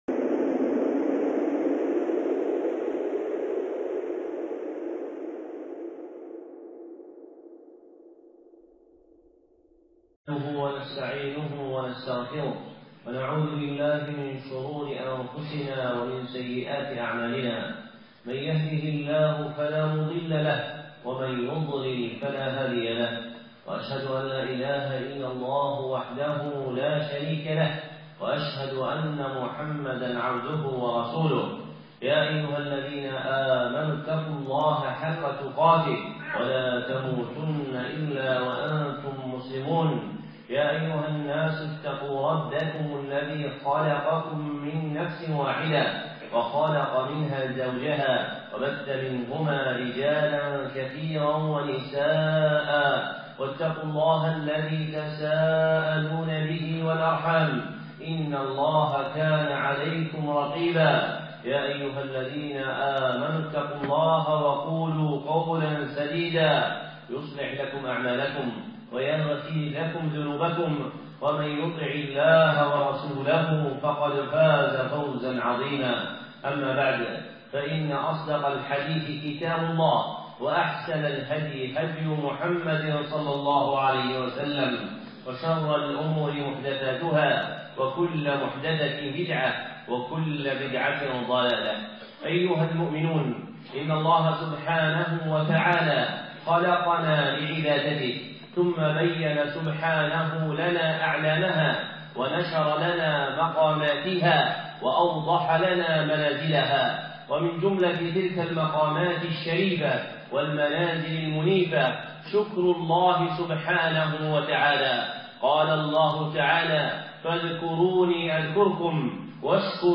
خطبة (مقام الشكر لله عز وجل